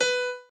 b_piano1_v100l4o5b.ogg